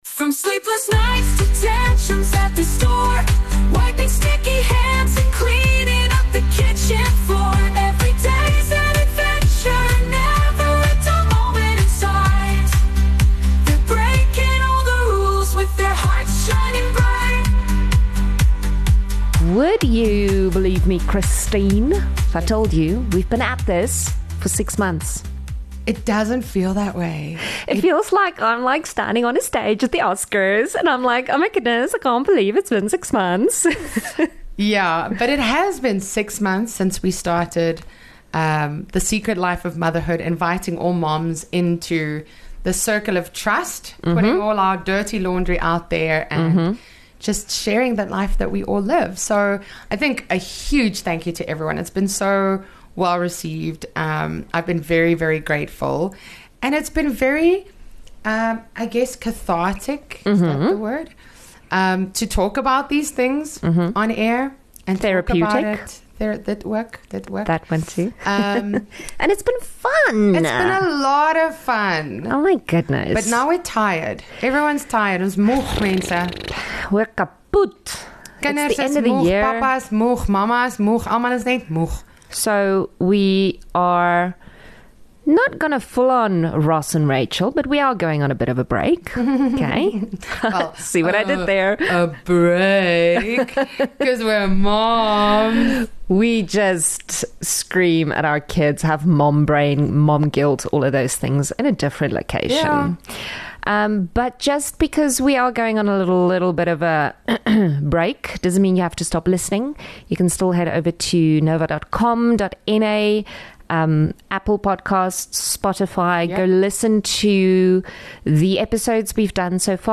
26 Nov Episode 22 - Bloopers